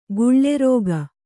♪ guḷḷe rōga